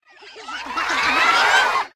Golf_Crowd_Buildup.ogg